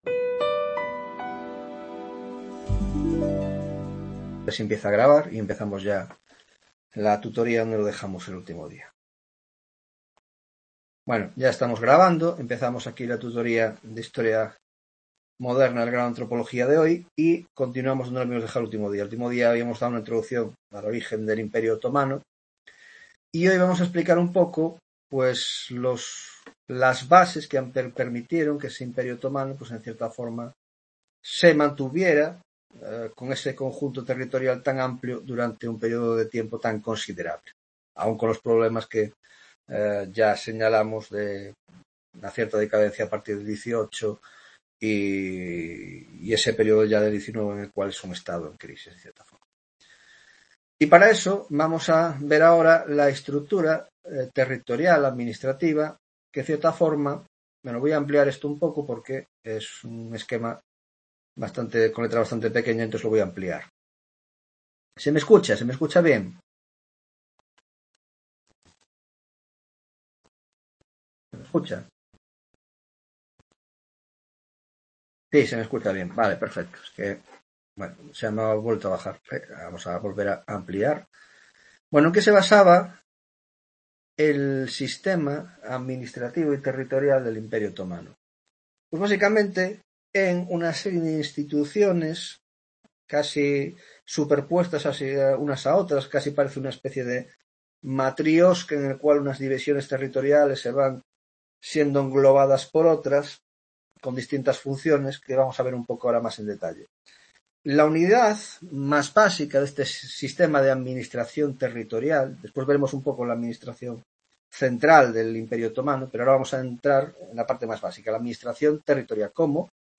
10ª Tutoria Historia Moderna (Grado de Antropologia Social y Cultural)